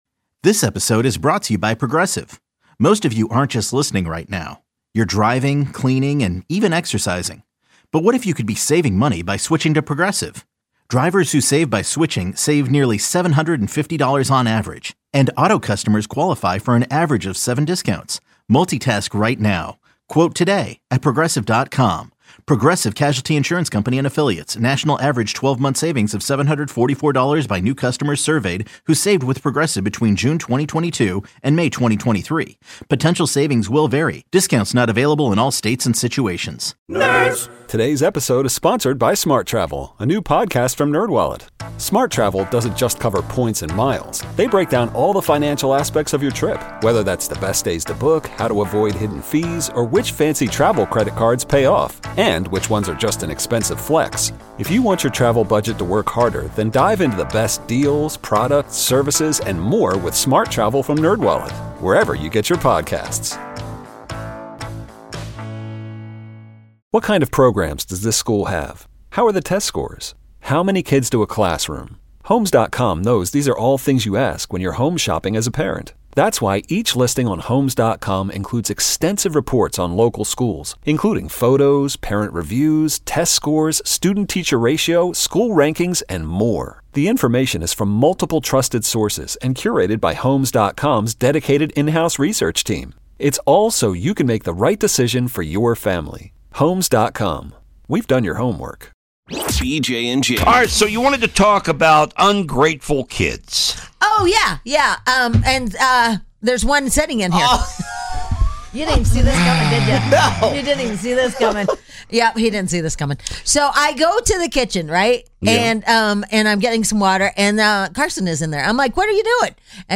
Irreverent, funny, and real-life radio